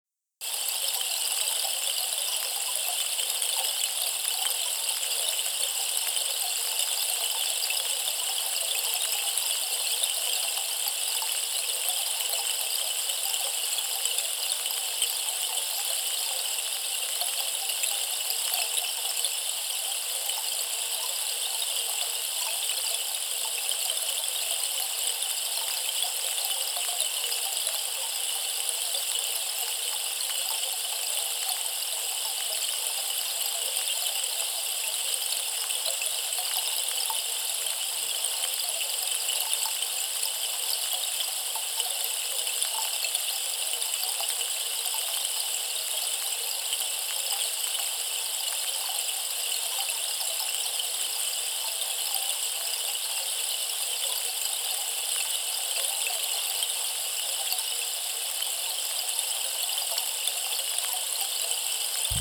Hudební nástroj z břízy nese lehký, osvěžující a čistý tón. Není hlasitý, ale zní jasně – jako první jarní den, jako první nádech po dlouhé zimě.
Svým zvukem přináší do místnosti klidnou a osvěžující atmosféru.
Poslechněte si zvuky tekoucí vody, zklidněte roztěkanou mysl a uvolněte napjaté tělo.
Díky různorodé směsi, kterou je deštný sloup naplněn, vydává bohatý a překvapivě rozmanitý zvuk.
Zvuková ukázka deštný sloup bříza 70 cm (wav, 5.2 MB)